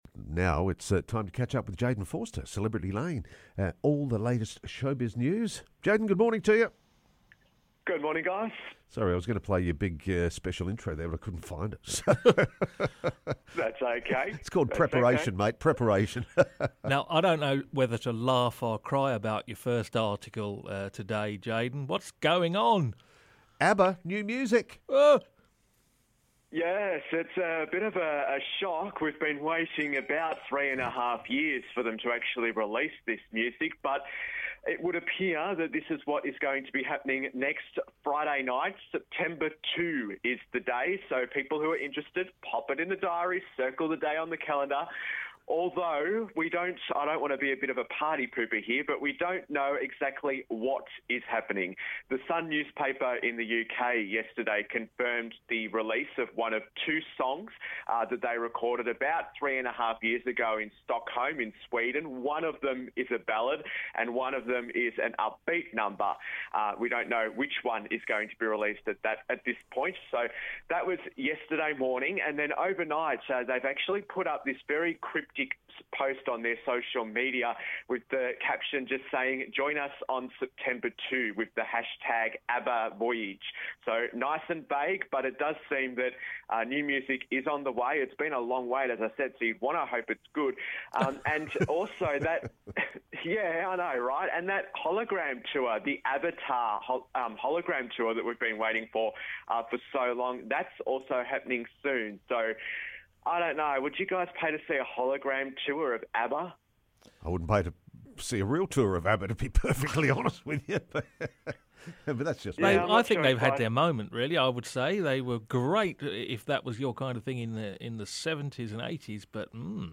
regular entertainment report